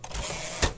史密斯科罗纳电动打字机 " 单台电动打字机的回车器
单次打字机车厢返回。
Edirol R4 +一对Behringer C2小电容。
Tag: 电动打字机 机器 机械 噪声 办公室的声音 单触发 打字机 打字